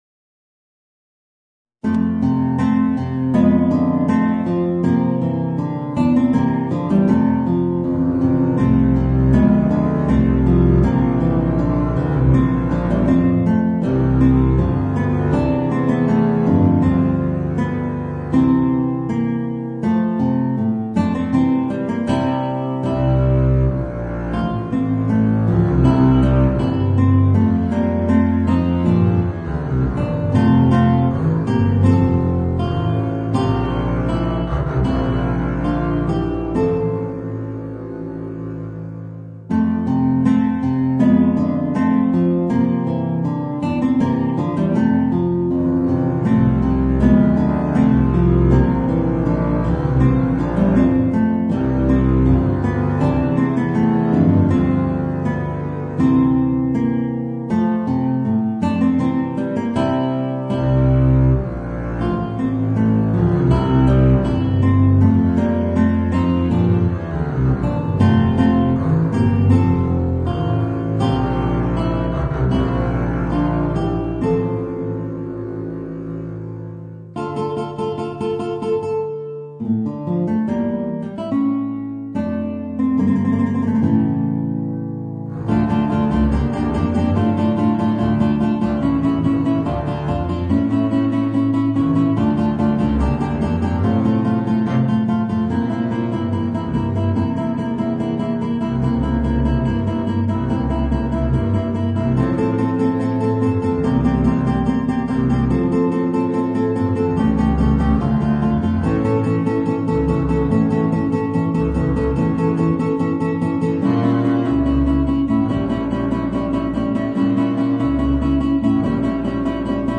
Voicing: Contrabass and Guitar